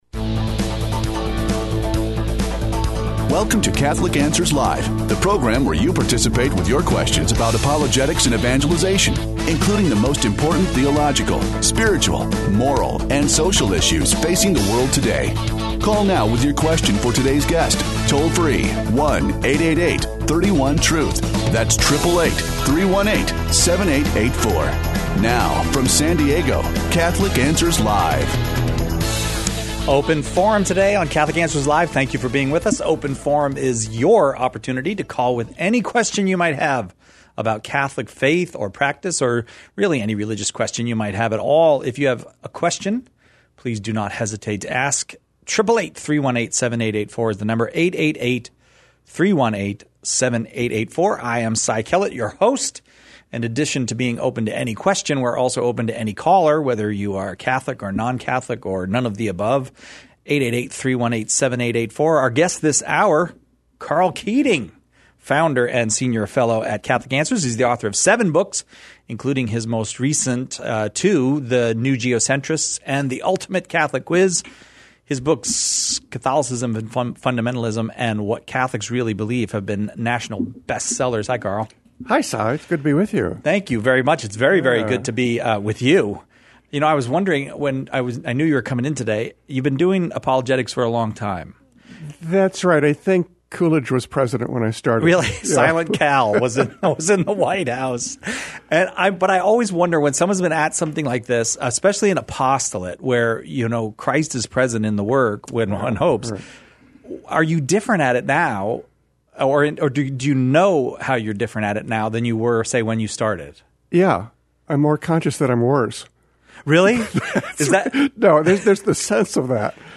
The callers choose the topics during Open Forum, peppering our guests questions on every aspect of Catholic life and faith, the moral life, and even philosophic...